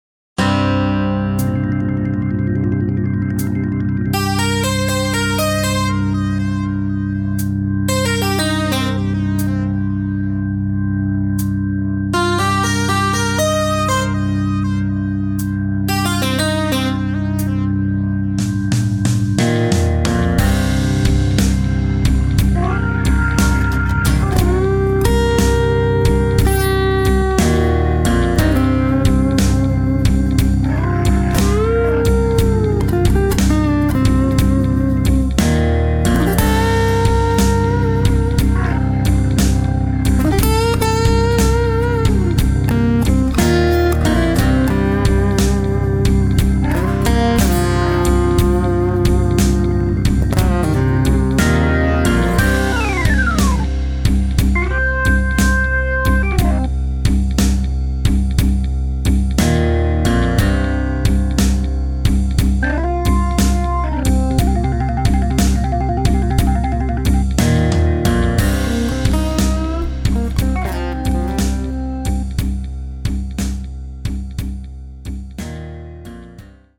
Keyboards
Guitar, Bass